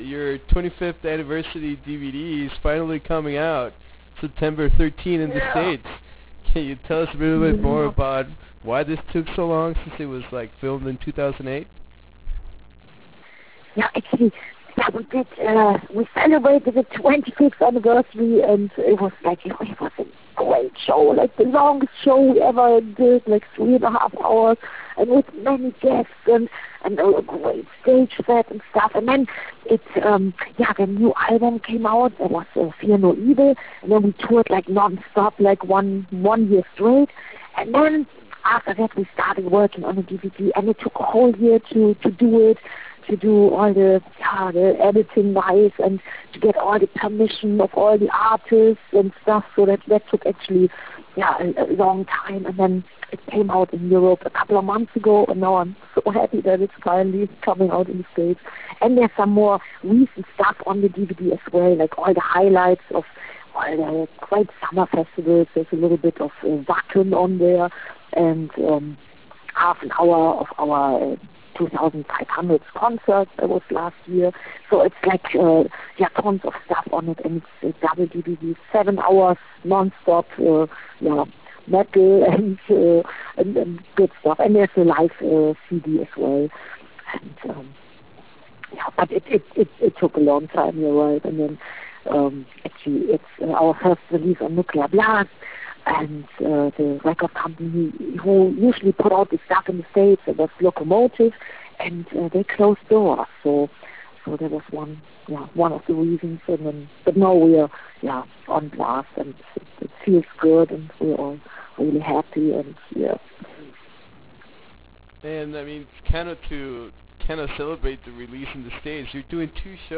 Interview with Doro Pesch (2011)
Interview with Doro Pesch - 2011.wav